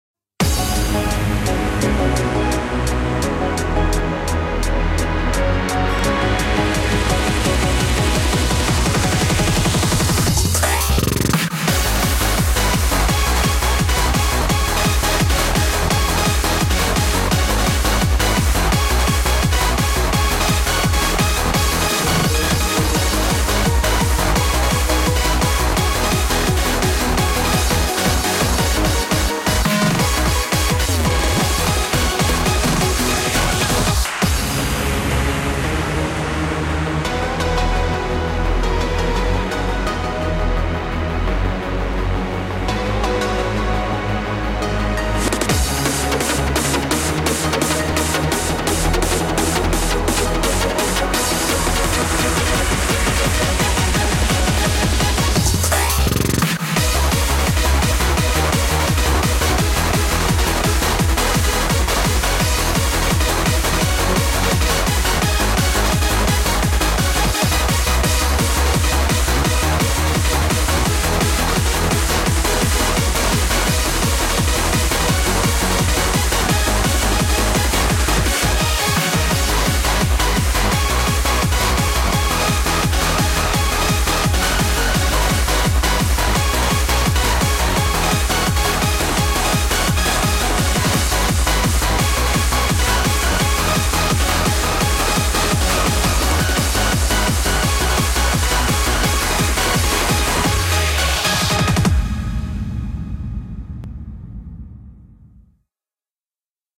BPM85-170
Audio QualityPerfect (Low Quality)